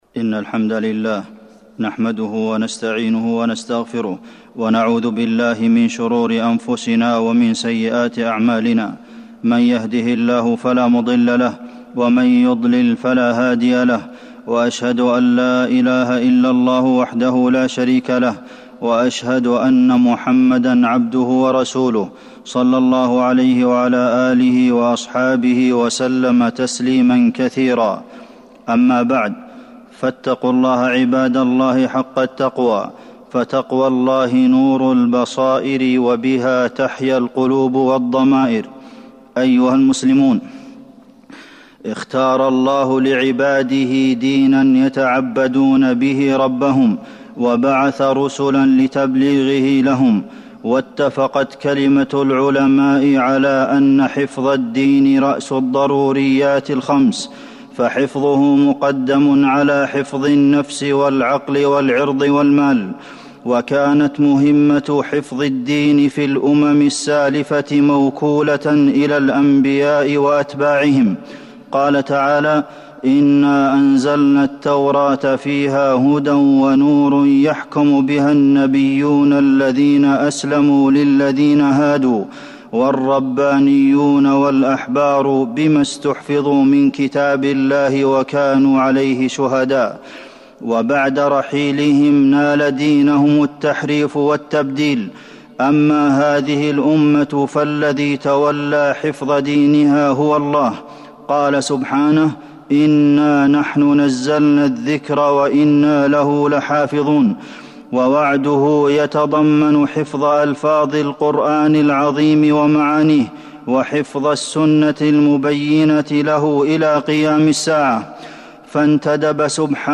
تاريخ النشر ٥ ربيع الثاني ١٤٤٢ هـ المكان: المسجد النبوي الشيخ: فضيلة الشيخ د. عبدالمحسن بن محمد القاسم فضيلة الشيخ د. عبدالمحسن بن محمد القاسم حفظ الله للدين